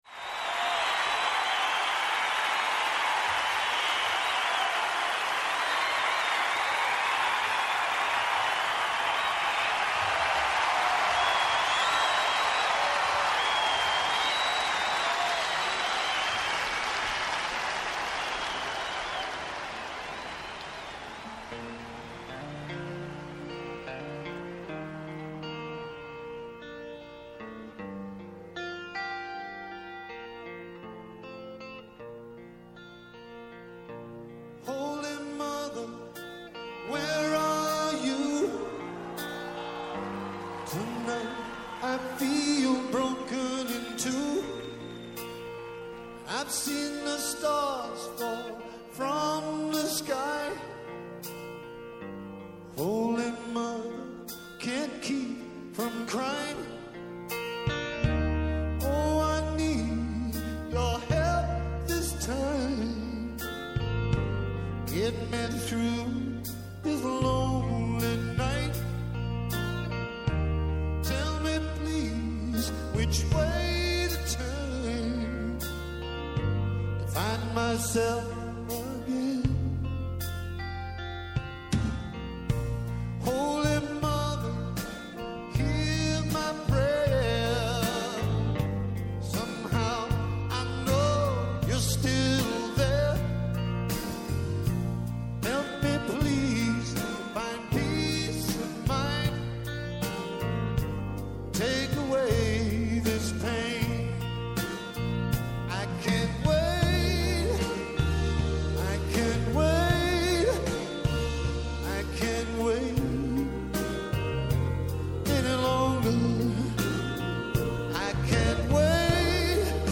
Καλεσμένοι τηλεφωνικά στην σημερινή εκπομπή